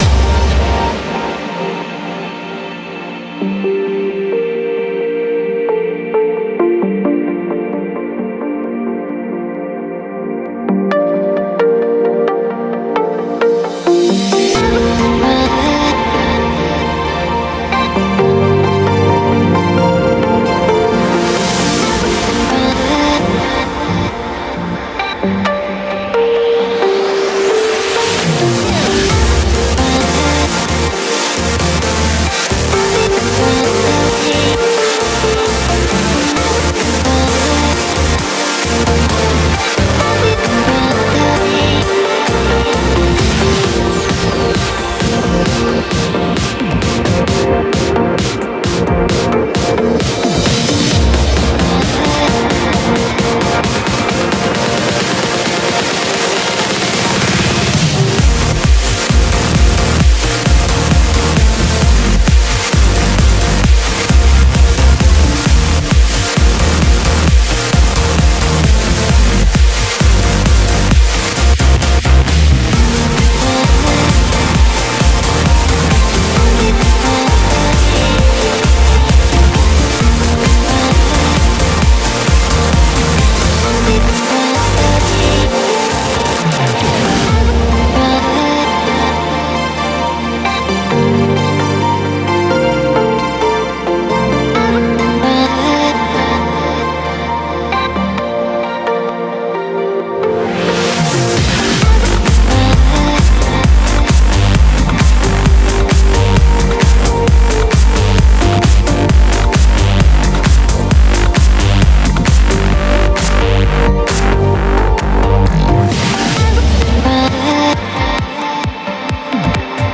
BPM66-132
MP3 QualityMusic Cut
Get ready for some progressive trance.